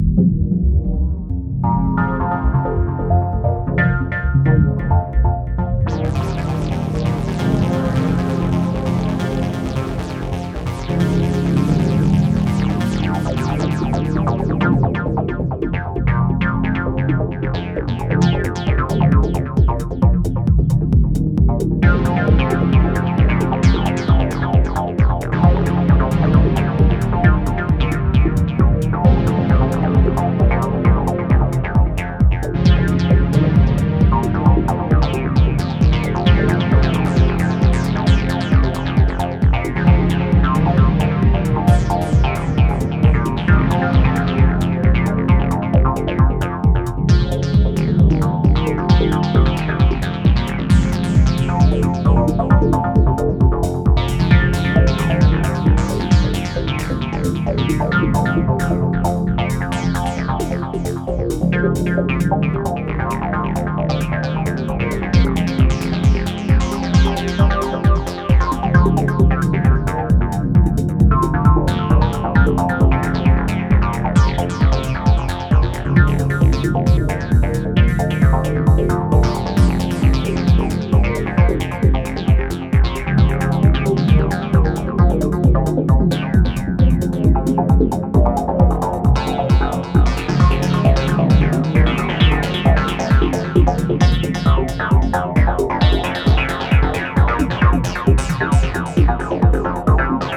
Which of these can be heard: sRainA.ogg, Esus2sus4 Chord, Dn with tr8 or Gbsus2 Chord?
Dn with tr8